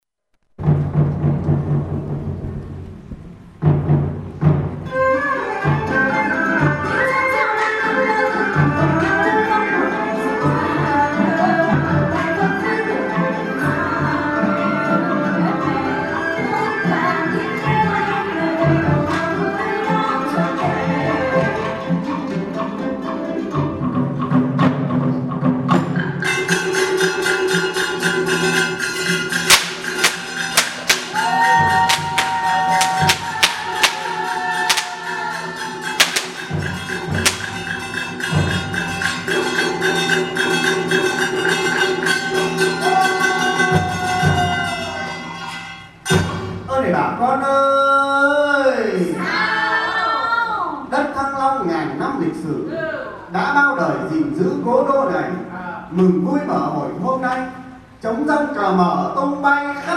Am Abend war dann ein Muß von Hanoi, das Wasserpuppentheater, angesagt. Bei fremd klingender Musik wurde ein buntes Puppenspiel aufgeführt.